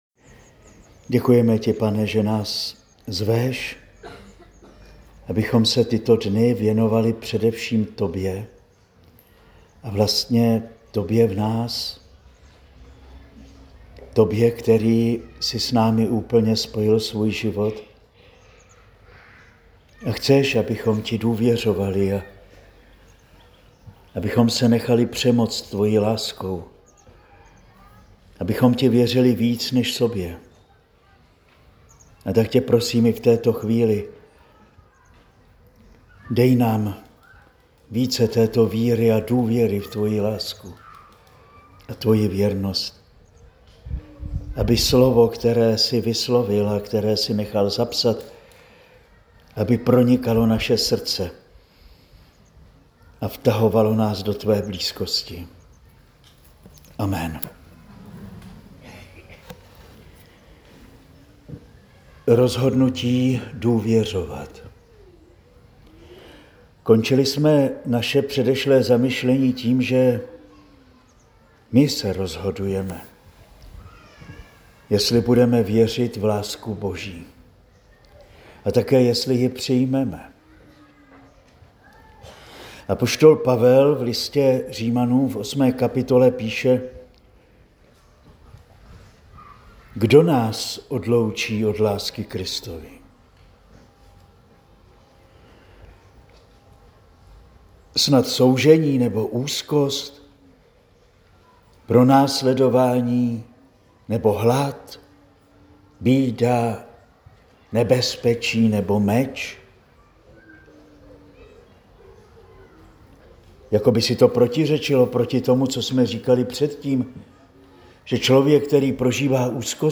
Nyní si můžete poslechnout třetí promluvu.